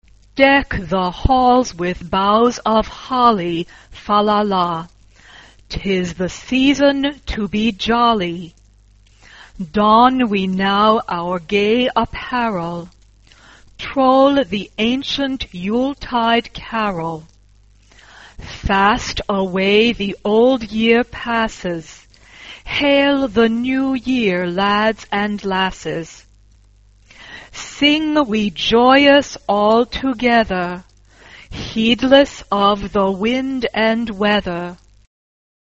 SA (2 voix égale(s) d'enfants) ; Partition complète.
Chant de Noël ; Carol Caractère de la pièce : exalté
Instrumentation : Piano
Tonalité : mi bémol majeur